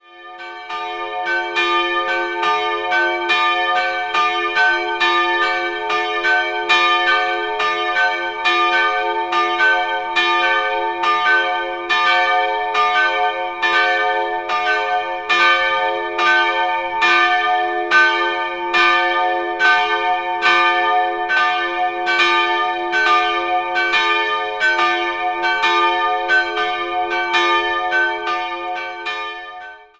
2-stimmiges Geläut: es''-g''(tief) Die kleine Glocke stammt aus der Zeit um 1400, die größere wurde 1719 von Heroldt (Nürnberg) gegossen.